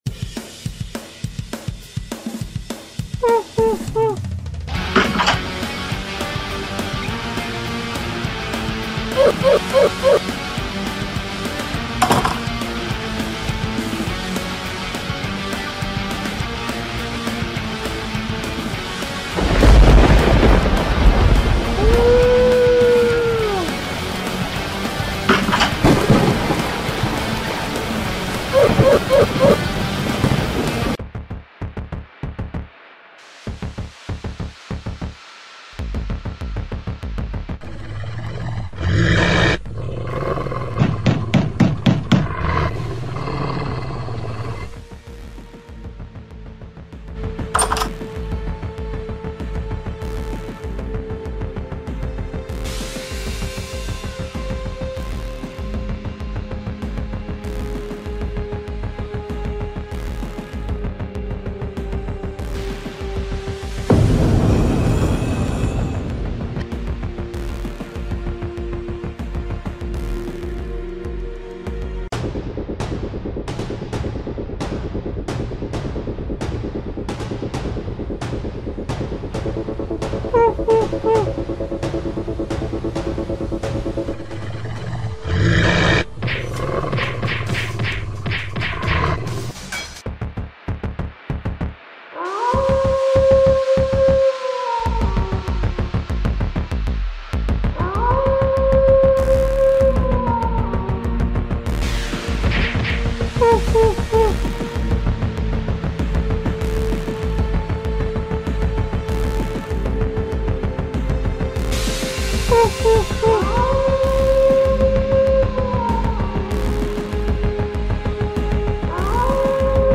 bew wow wow Dog Animation sound effects free download